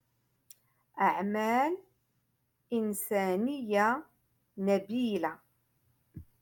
Moroccan Dialect- Rotation Five-Lesson sixty Three